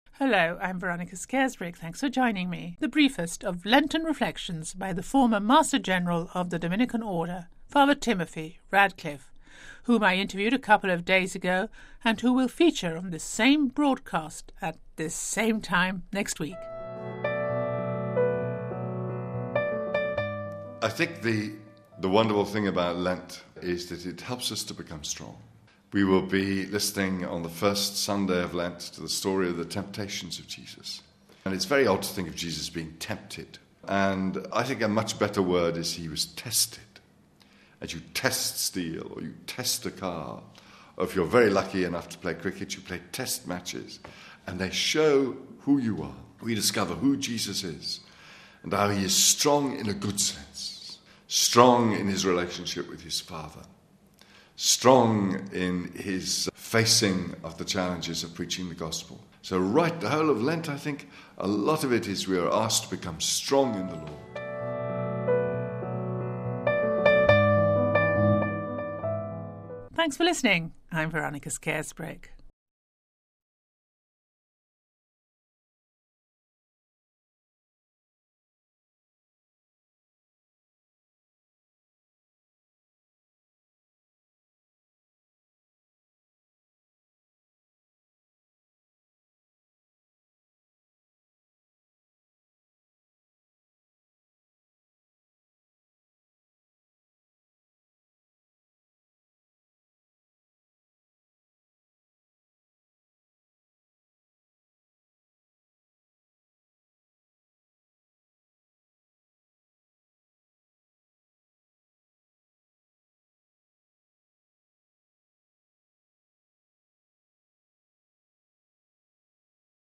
Well known preacher Dominican Father Timothy Radcliffe,who will feature at length here on Vatican Radio at this same time next week , offers us the briefest of Lenten reflections...